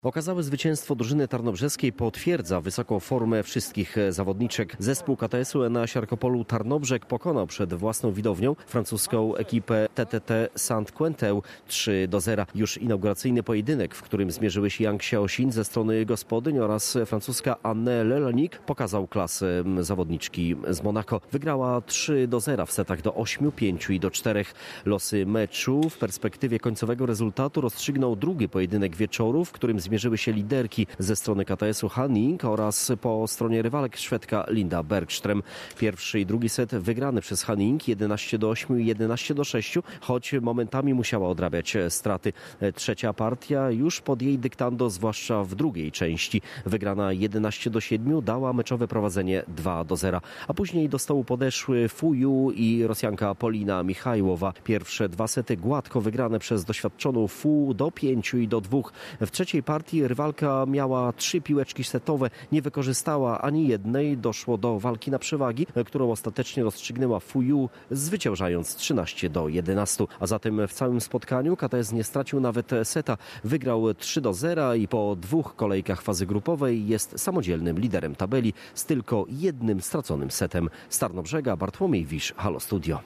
Z Tarnobrzega relacjonuje